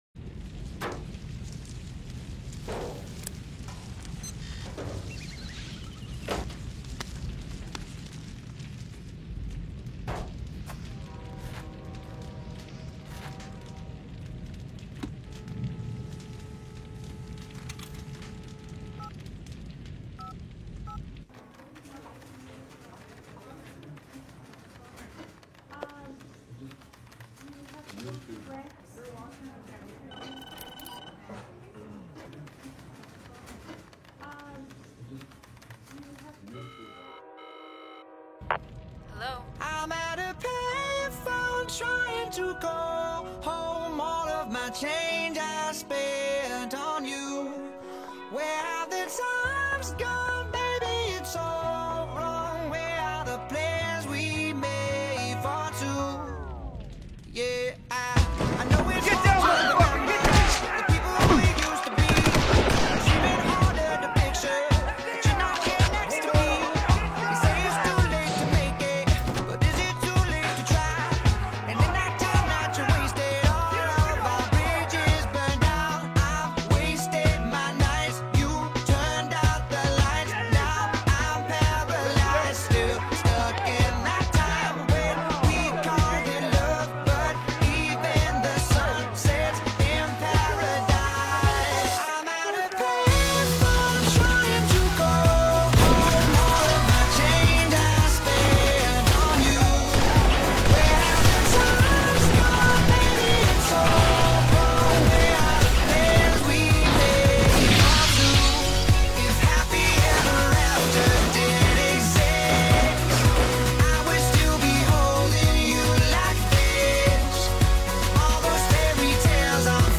pop rock
Me gusta porque su música es muy pegadiza y me motiva mucho.